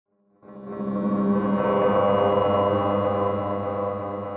A segment of the "Glass Pour" audio, with an added audio filter onto it. This sound is correlated with the letter "r" on the computer keyboard.